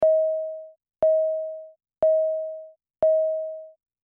Car Warning Sound 2